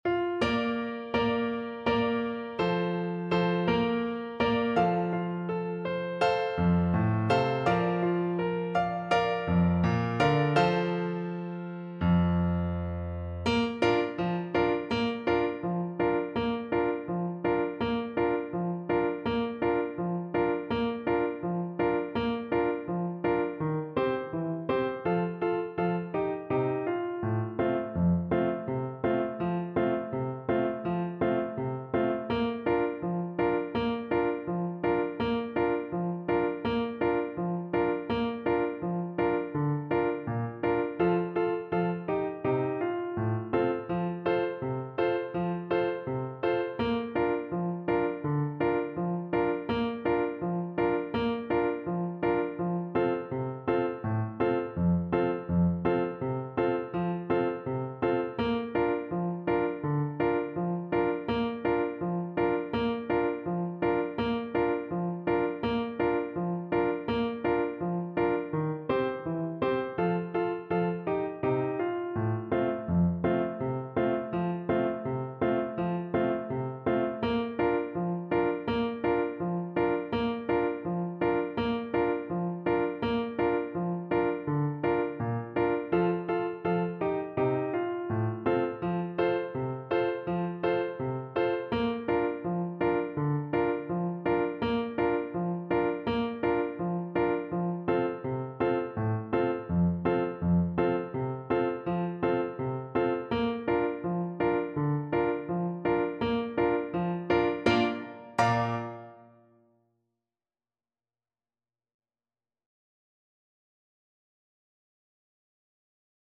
French Horn
Bb major (Sounding Pitch) F major (French Horn in F) (View more Bb major Music for French Horn )
Allegro (View more music marked Allegro)
2/4 (View more 2/4 Music)
D4-F5
Mexican